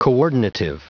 Prononciation du mot coordinative en anglais (fichier audio)
Prononciation du mot : coordinative